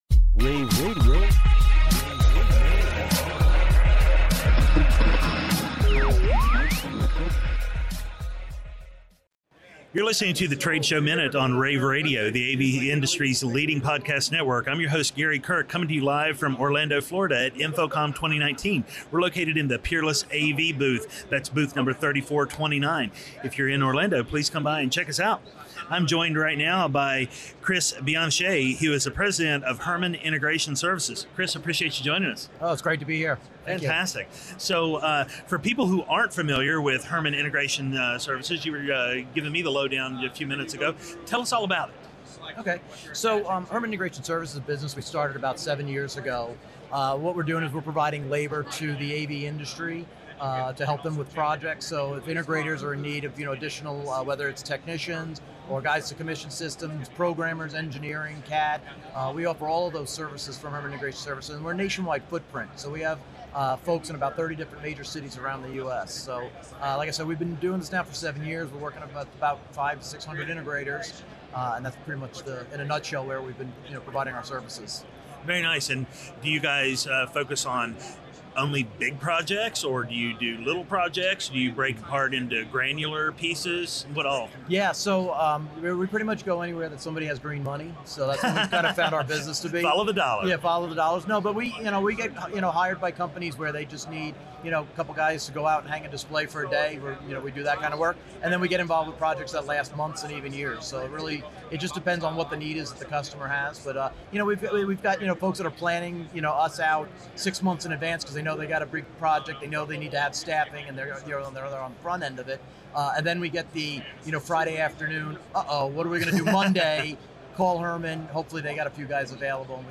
June 13, 2019 - InfoComm, InfoComm Radio, Radio, The Trade Show Minute,